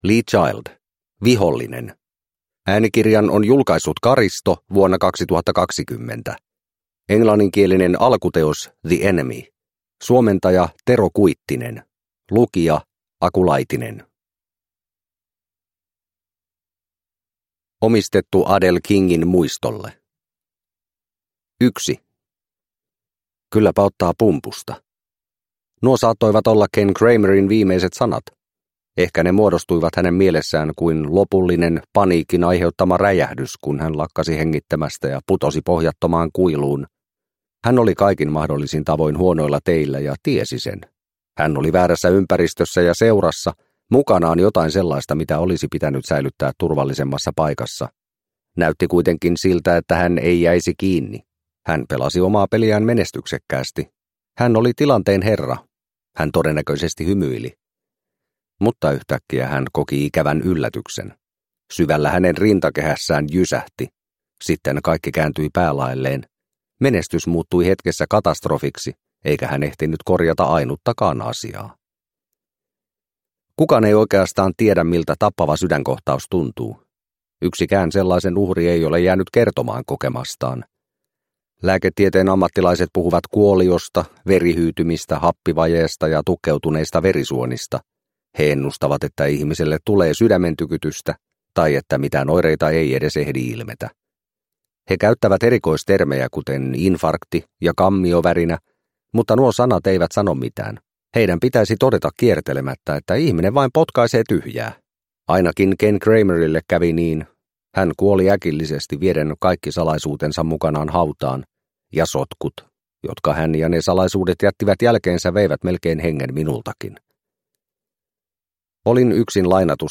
Vihollinen – Ljudbok – Laddas ner